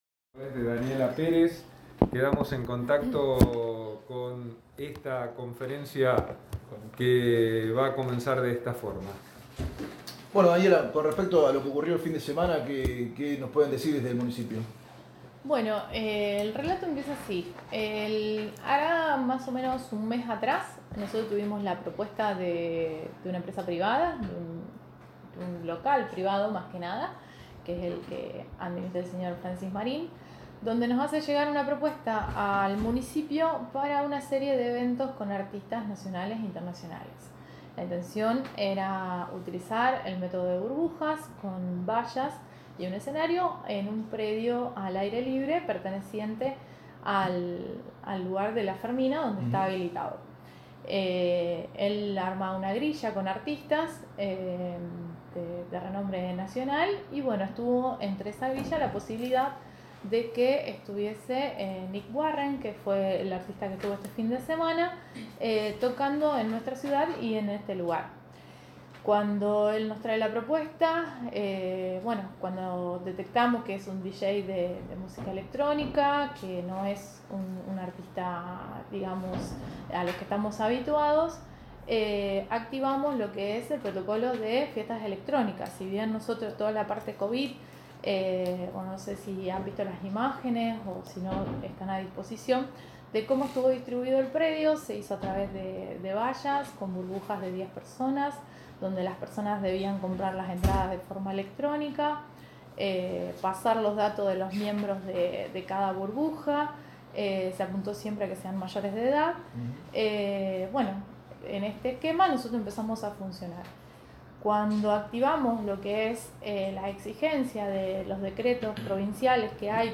En la mañana de hoy, la Secretaria de Gobierno de la Municipalidad de Armstrong Srta. Daniela Pérez, en conferencia de prensa hablo sobre la habilitación de la fiesta electrónica realizada en La Fe…